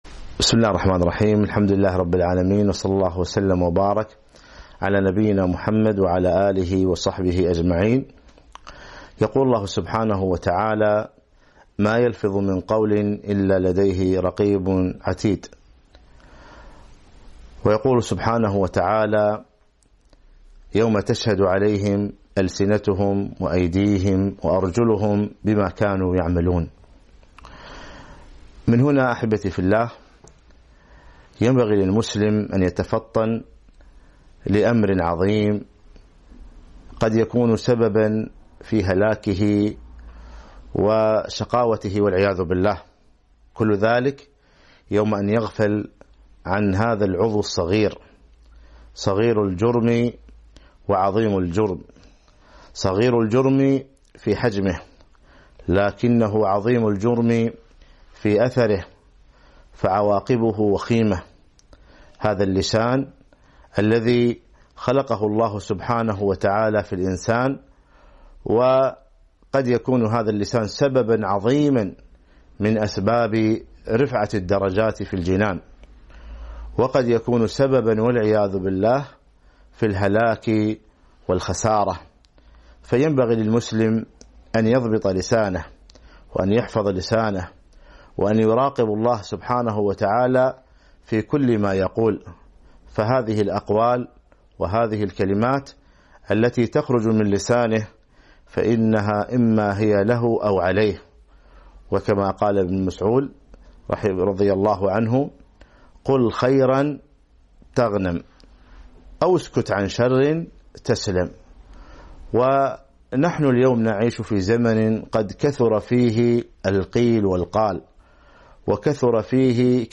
خطورة الكلمة - موعظة